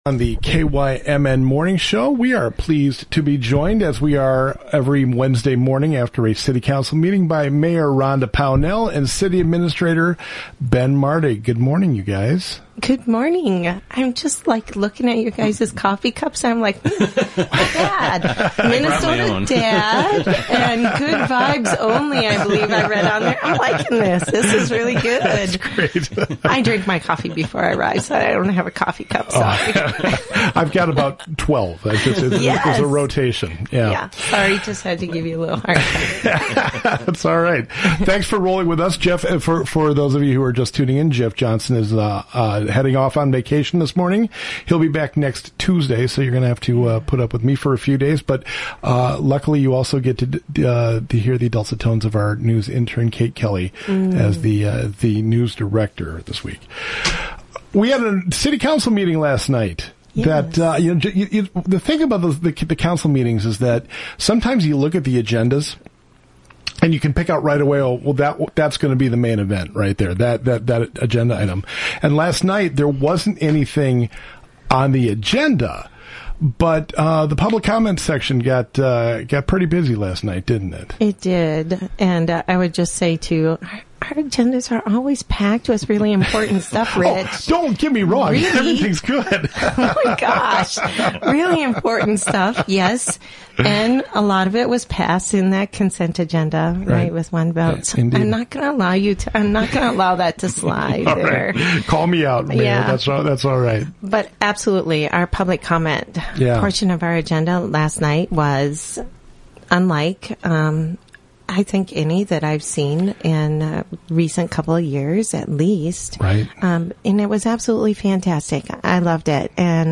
Northfield Mayor Rhonda Pownell and City Administrator Ben Martig discuss the July 12 City Council meeting including a public comment period on issues relating to the new Viking Terrace ownership.